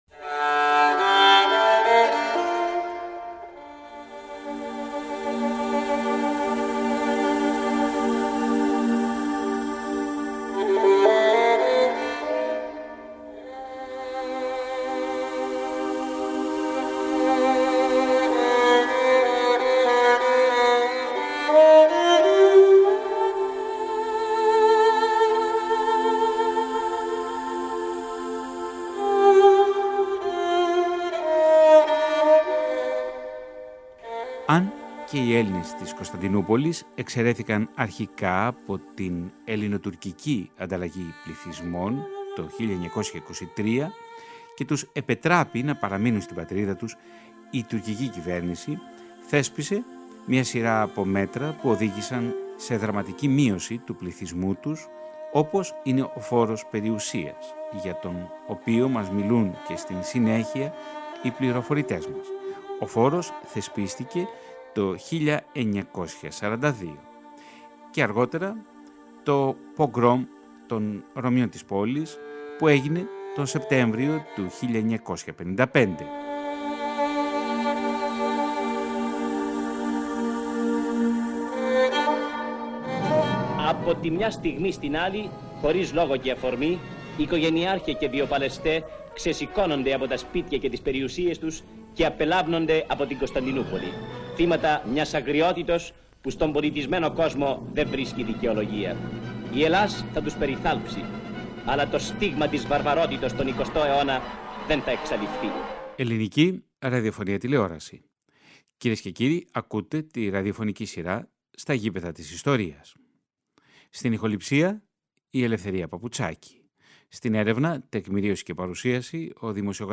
Οι ακροατές θα ταξιδέψουν στη συνοικία του Πέραν και στο Καράκιοϊ και θα ακούσουν τρεις συγκλονιστικές μαρτυρίες απελαθέντων, που έζησαν όλα τα γεγονότα πριν την ταπεινωτική διαδικασία της απέλασης, όταν οι λίστες των απελαθέντων δημοσιεύονταν στις τουρκικές εφημερίδες, ένα γεγονός που άλλαξε τις ζωές των πρωταγωνιστών αλλά και των οικογενειών τους για πάντα.
ΝΤΟΚΙΜΑΝΤΕΡ